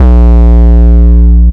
Hard 808 (JW2).wav